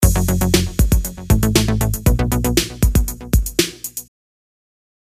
stereo-music-clip.wav